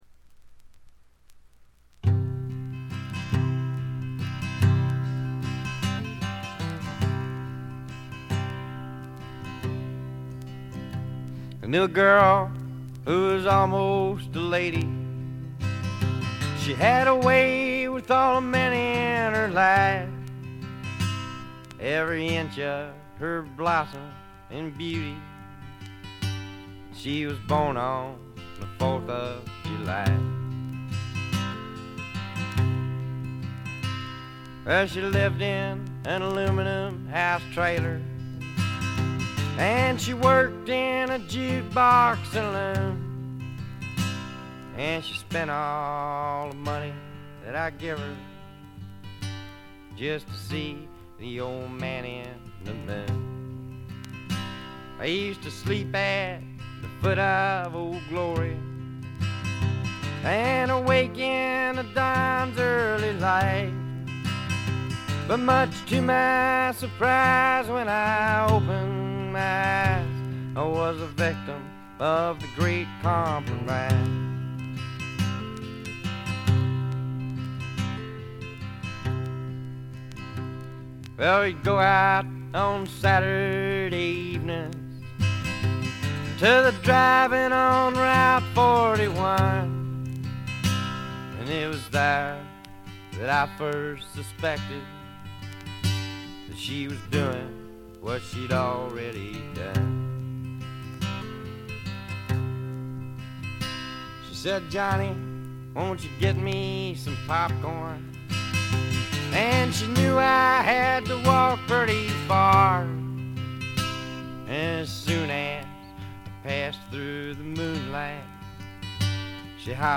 バックグラウンドノイズ、チリプチ多め大きめ。ところどころで散発的なプツ音。
アパラチアのマウンテンミュージックに根ざしたアコースティックなサウンドが心地よいです。
試聴曲は現品からの取り込み音源です。
mandolin, fiddle, banjo, vocals